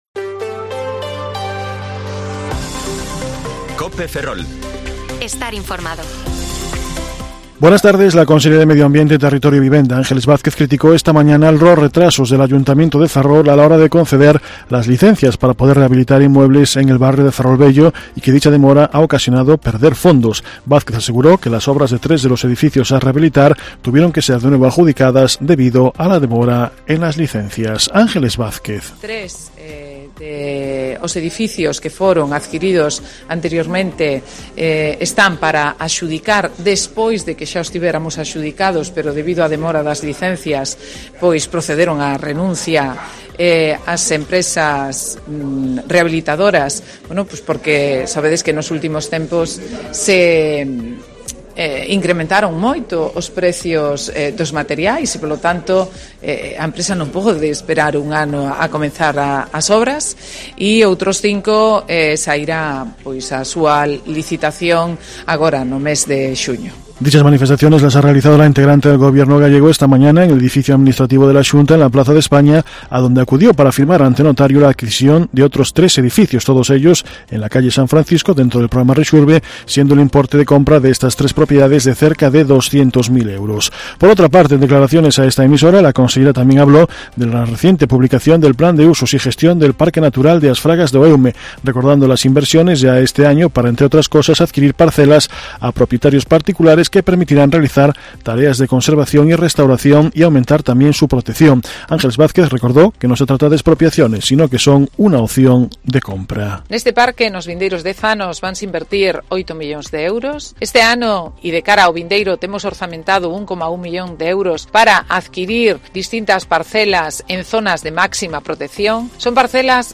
Informativo Mediodía COPE Ferrol 27/3/2023 (De 14,20 a 14,30 horas)